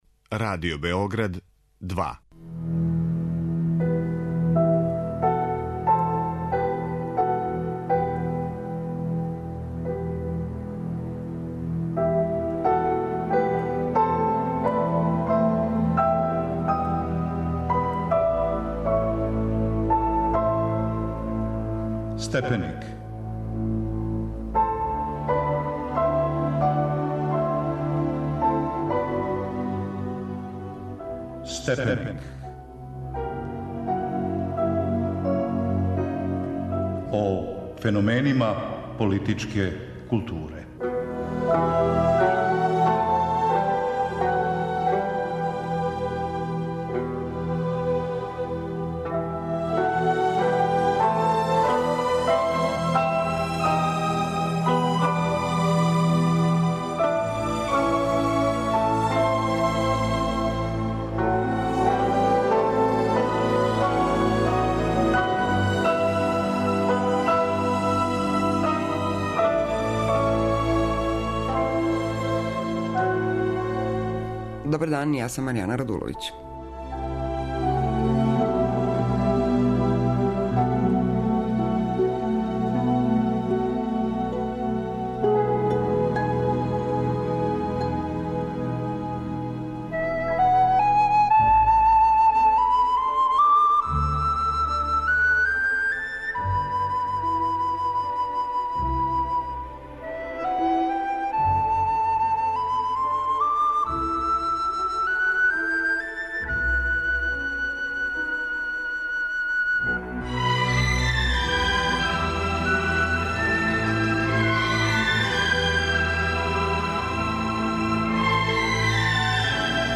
Разговарамо са професорима